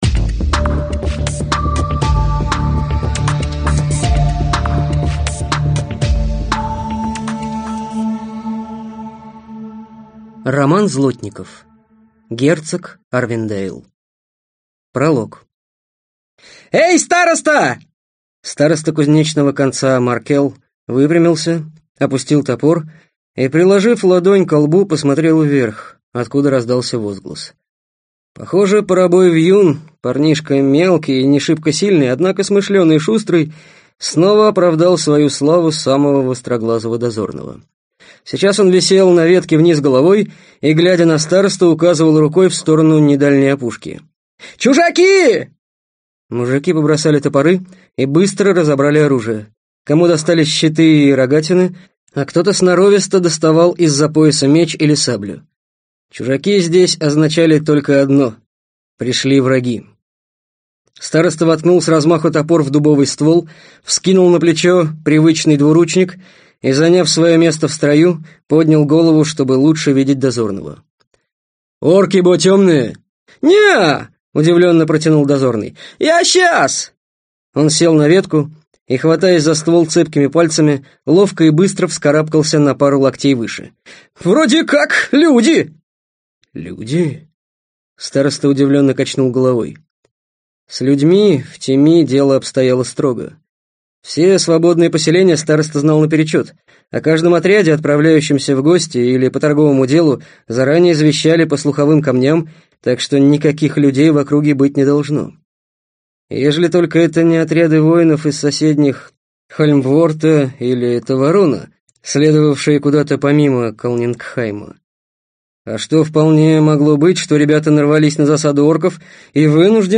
Аудиокнига Герцог Арвендейл - купить, скачать и слушать онлайн | КнигоПоиск